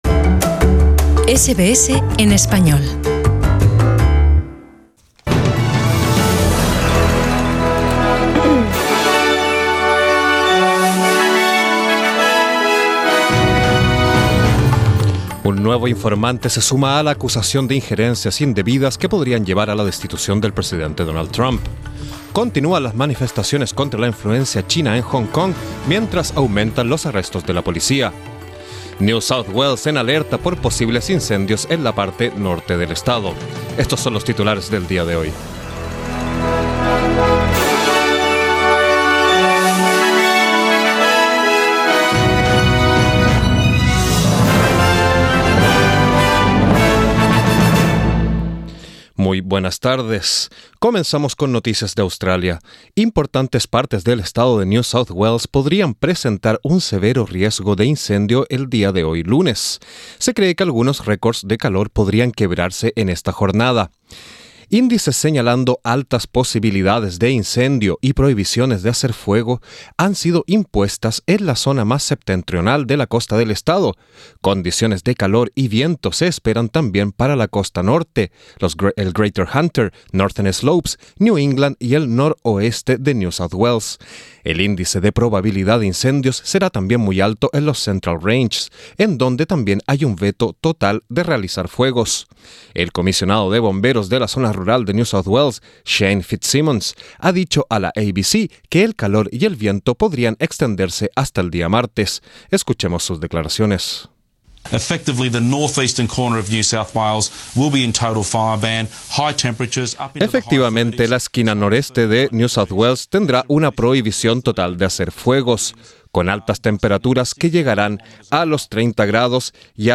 Noticias SBS Spanish | 7 octubre 2019